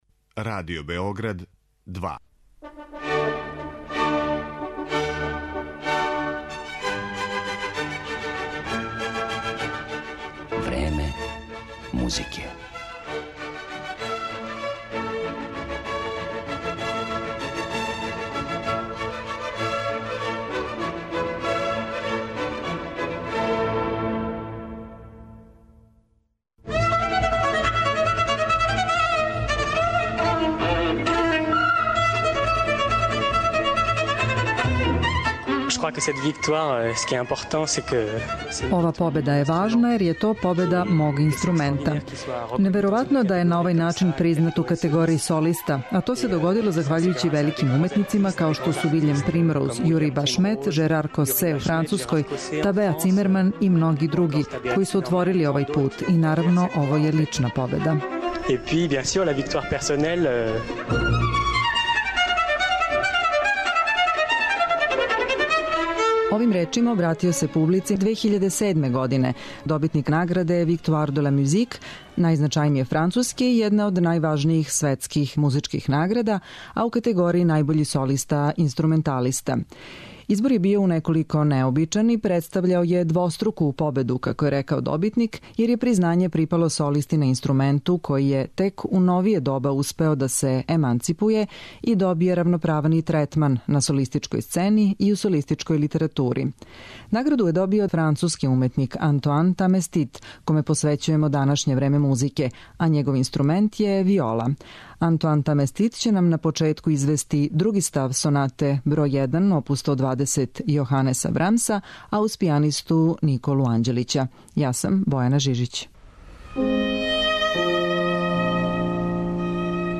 виолиста